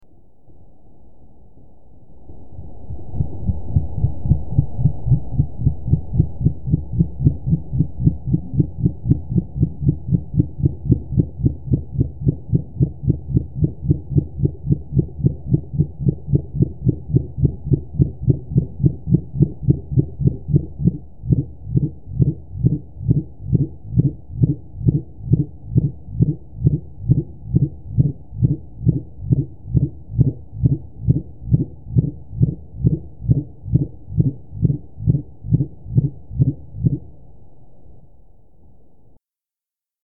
Minke Whale
VOCAL BEHAVIOR
Minke Whales produce groans, moans. The frequency of the vocalization is usually low-frequency. From 100 Hertz to 12 kHertz. You can listen a Common Minke Whale communicating in this recording collected by NOAA Fisheries.
minke-whale.mp3